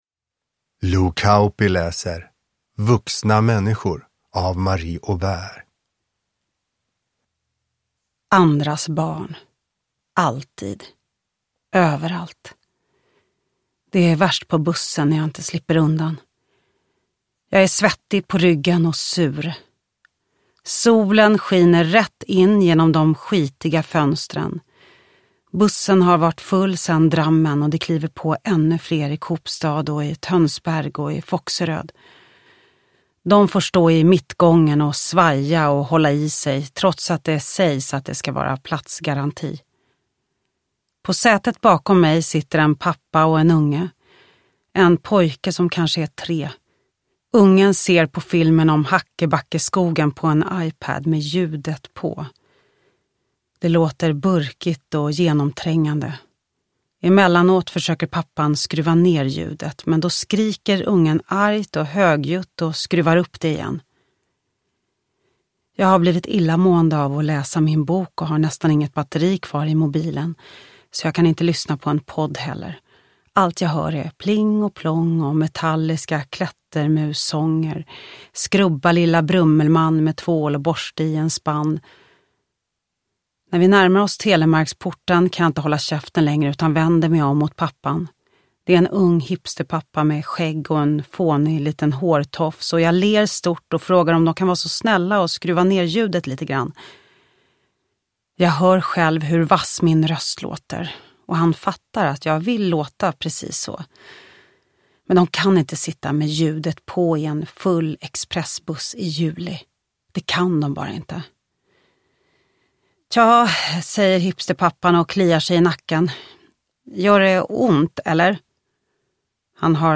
Vuxna människor – Ljudbok
Uppläsare: Lo Kauppi